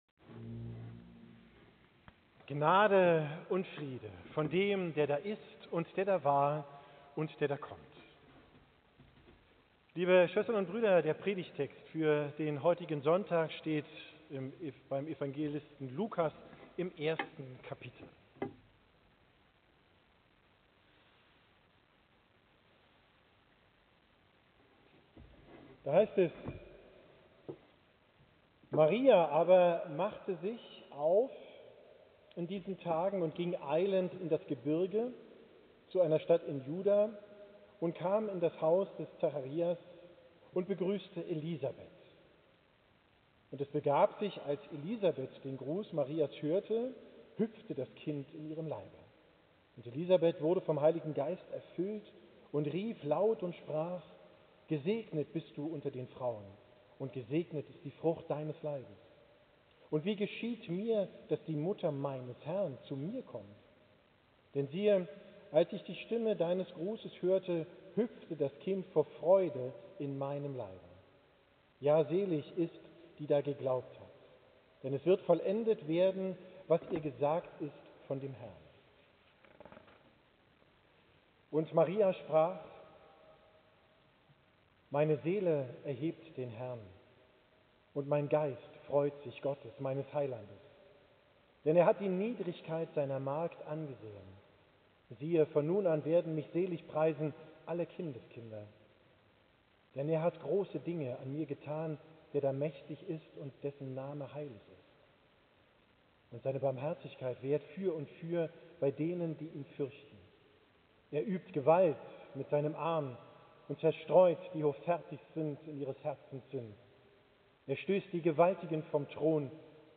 Predigt vom 3. Sonntag im Advent, 22. XII 24